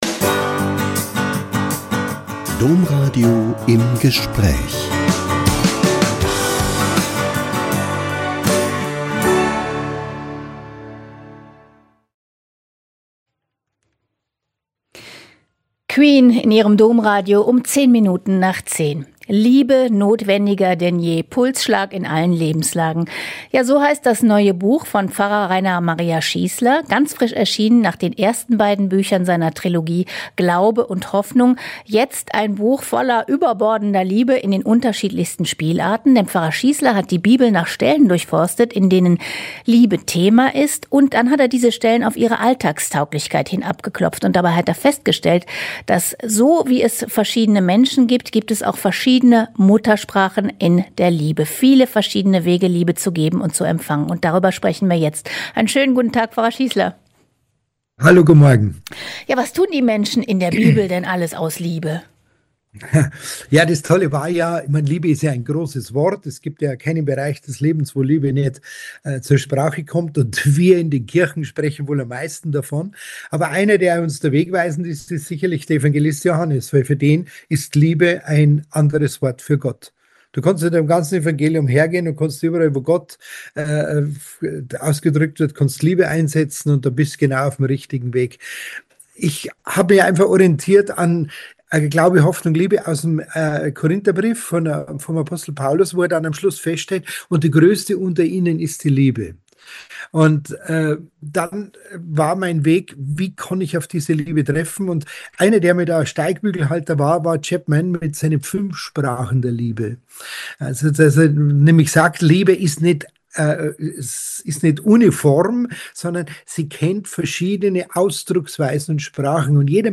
Pfarrer Rainer Maria Schießler spricht über Liebe in biblischen Texten